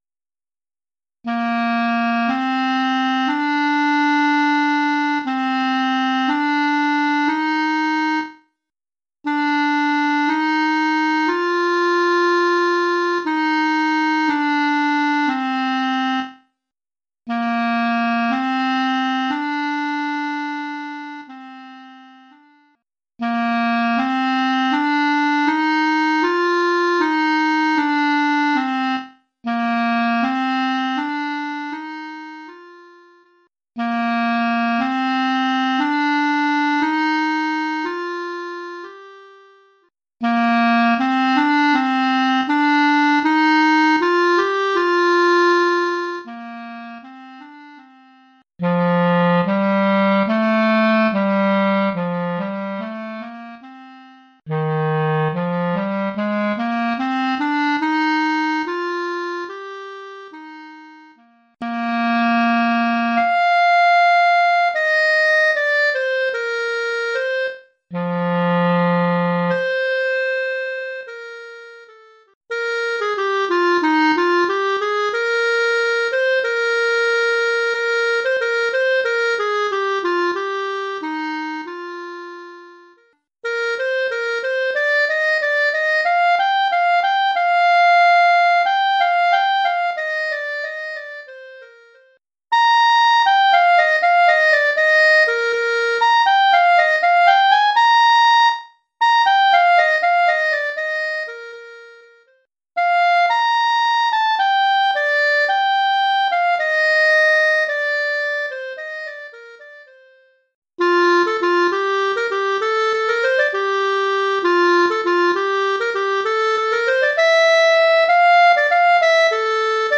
1 recueil : études pour clarinette